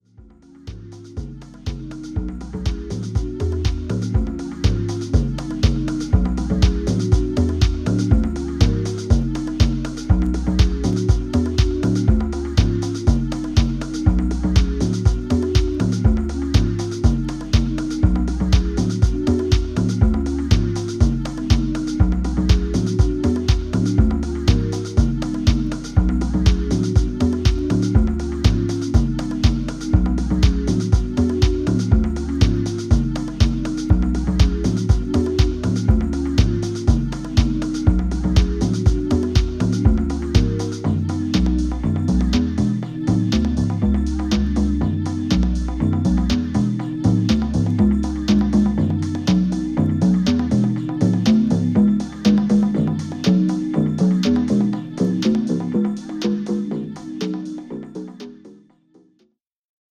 ホーム ｜ HOUSE / TECHNO > HOUSE